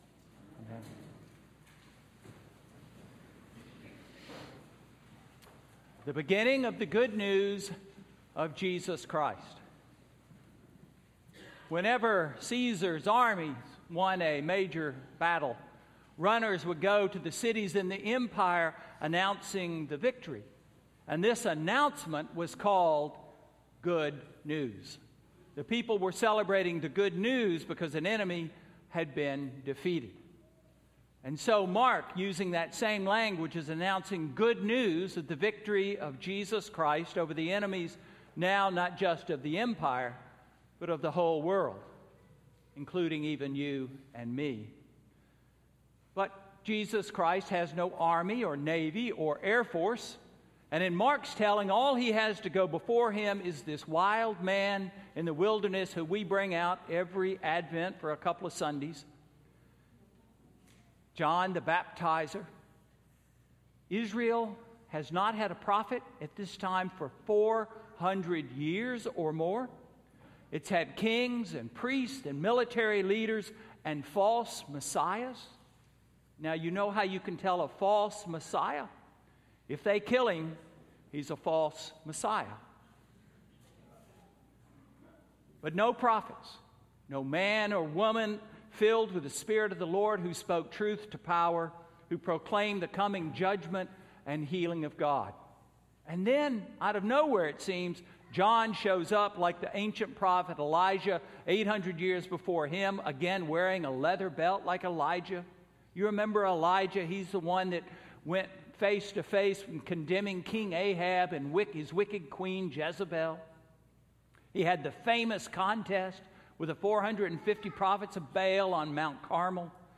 Sermon: Second Sunday of Advent–December 7, 2014